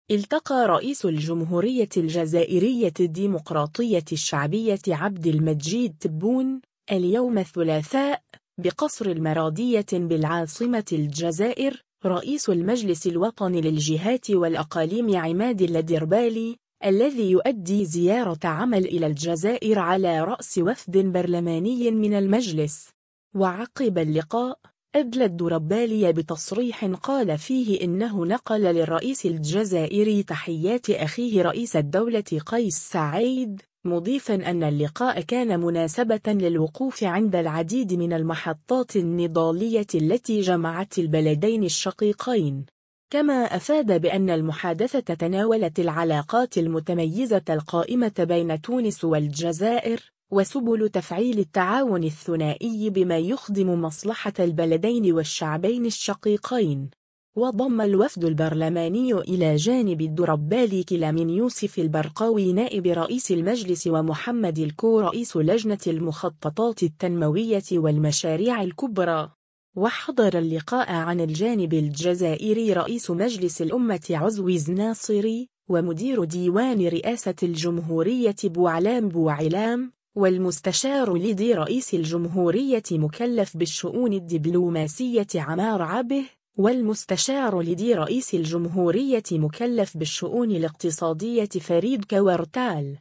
و عقب اللقاء، أدلى الدربالي بتصريح قال فيه إنه نقل للرئيس الجزائري تحيات أخيه رئيس الدولة قيس سعيّد، مضيفا أن اللقاء كان مناسبة للوقوف عند العديد من المحطات النضالية التي جمعت البلدين الشقيقين.